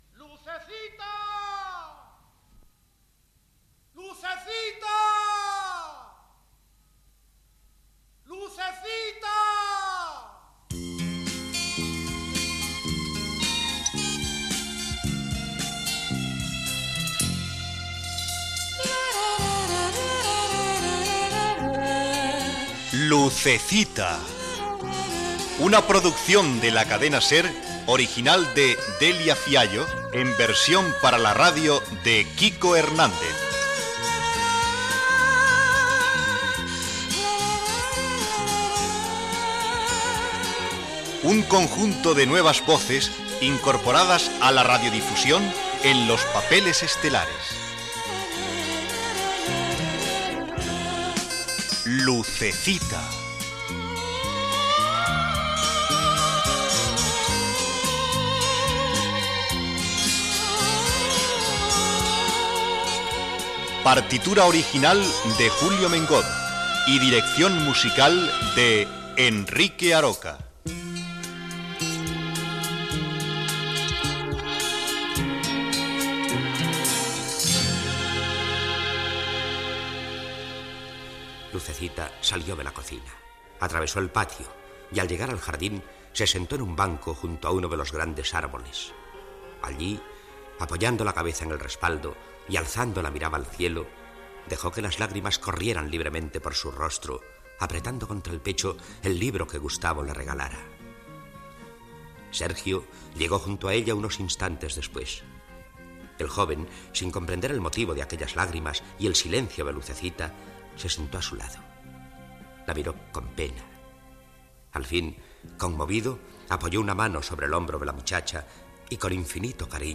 Careta del programa, Lucecita està plorosa amb un llibre a la mà i dialoga amb Sergio
Ficció